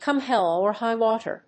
còme héll or hígh wáter